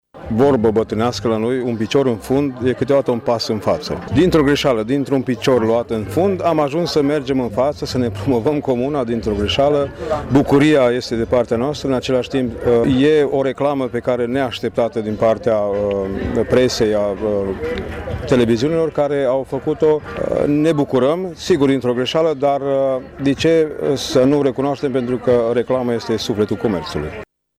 Primarul comunei, Laszlo Barta, spune că întâmplarea cu artistul american a fost un pas înainte pentru Bogata: